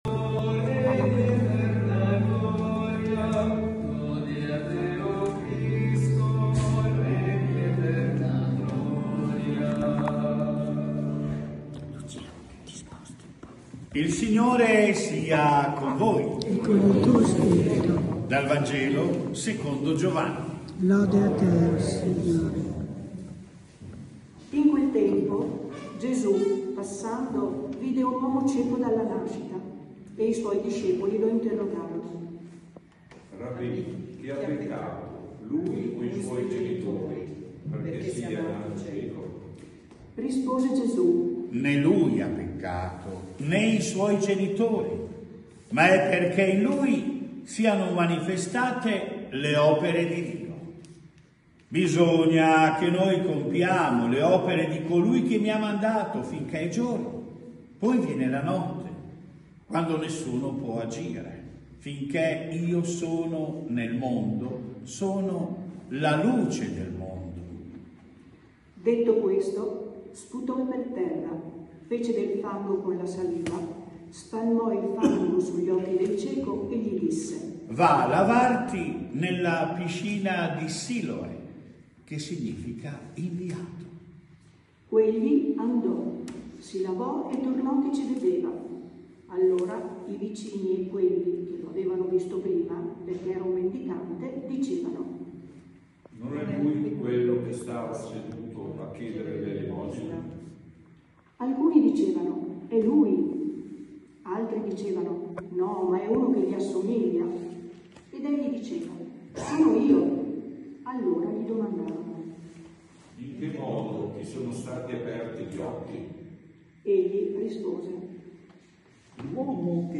Omelia IV quar. Anno A – Parrocchia San Pellegrino